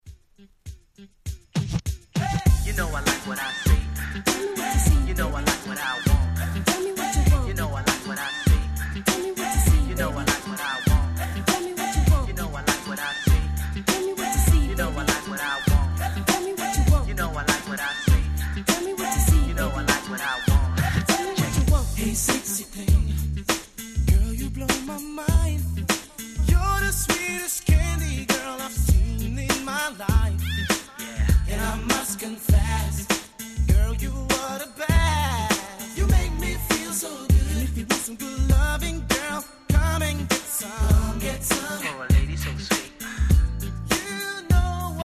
99' Nice Smooth R&B !!